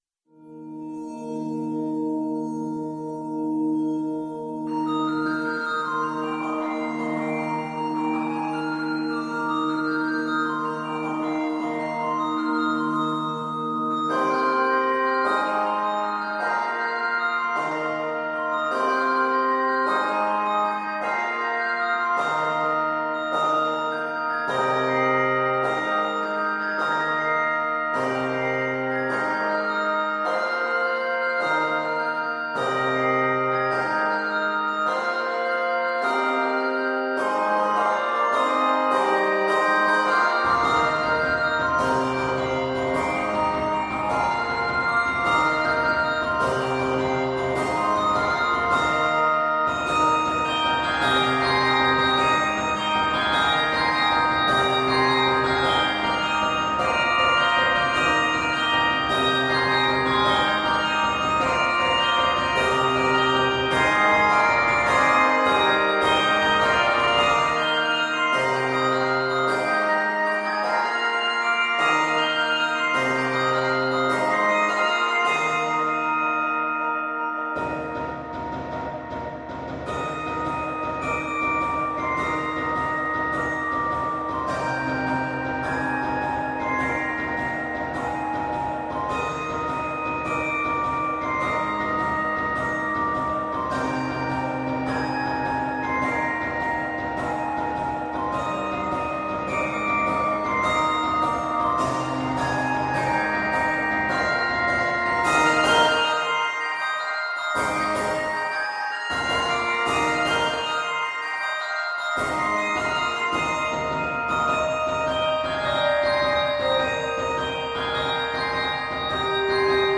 Scored in Eb Major, it is 144 measures.
Octaves: 5